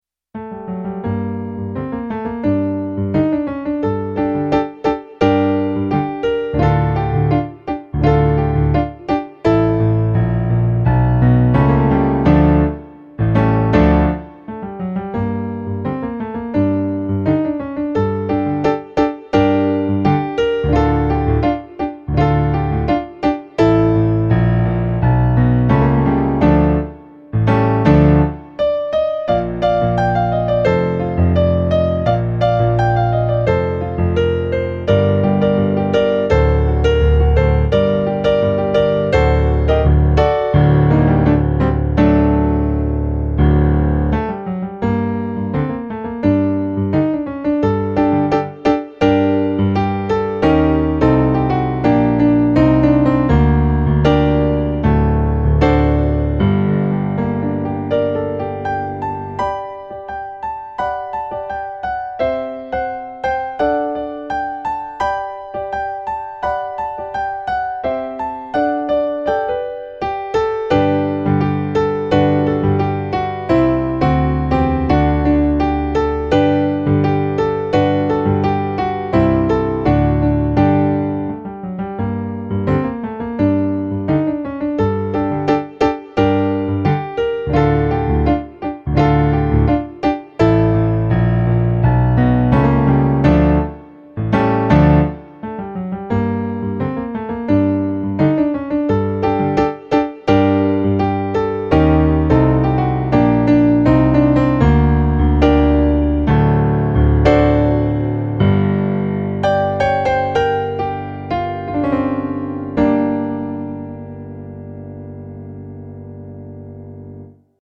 eight piano solo arrangements.  34 pages.
funk remix